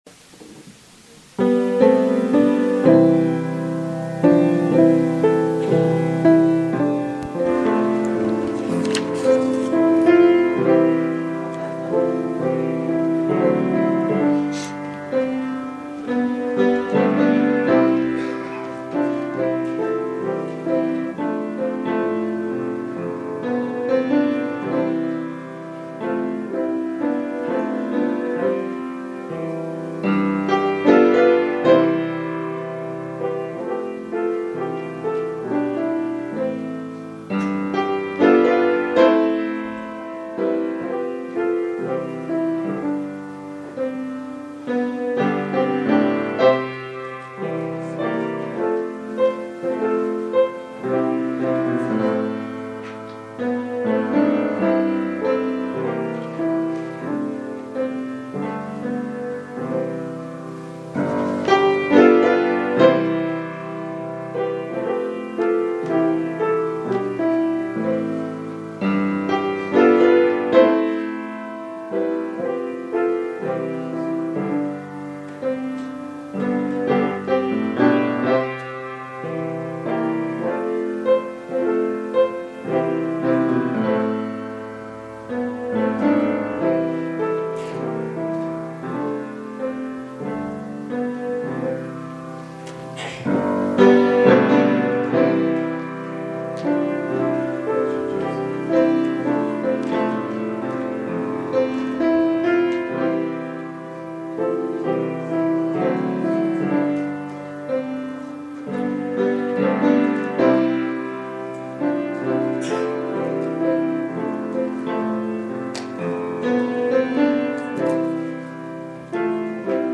The Gospel of Matthew Series (25 sermons)